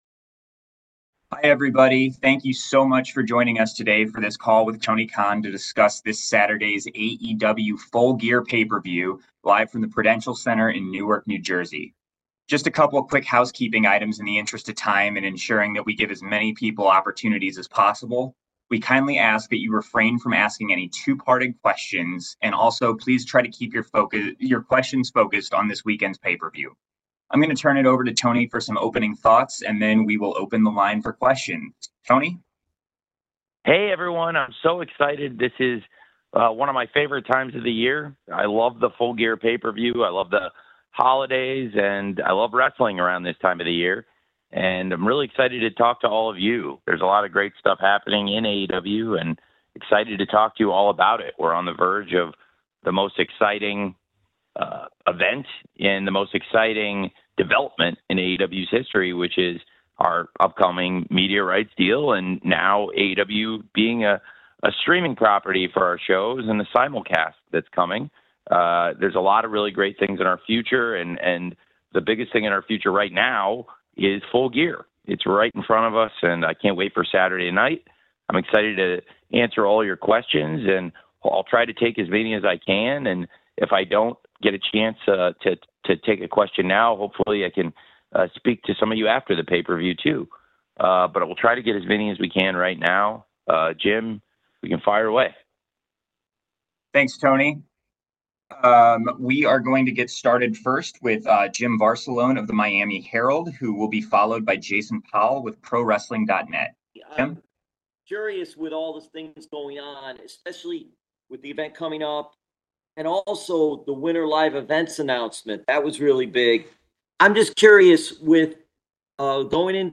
AEW Full Gear 2024 Media Call With Tony Khan
Tony Khan speaks with the media ahead of AEW Full Gear 2024 taking place on Saturday, November 23, 2024 at the Prudential Center in Newark, NJ.